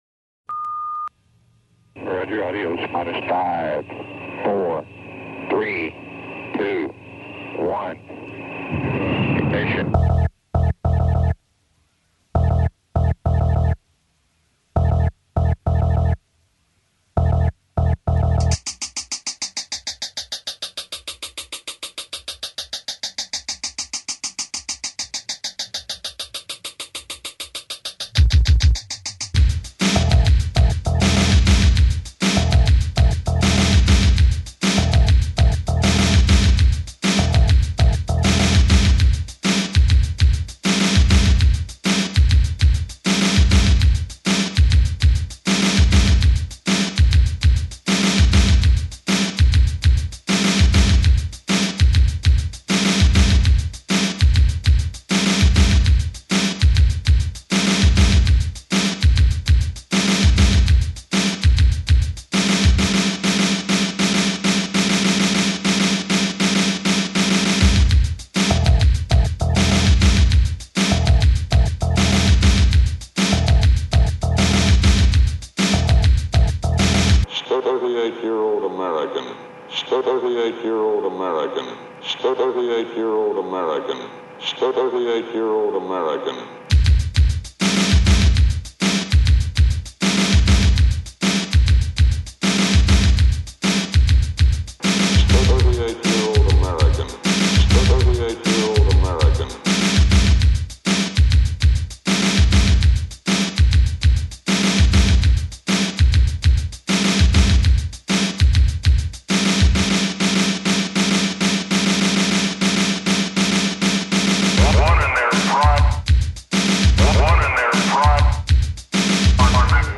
Metal Industrial Gothic Metal Heavy Metal